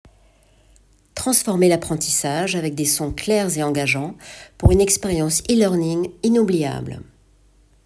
E-learning
49 - 60 ans - Mezzo-soprano